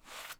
Default Draw.wav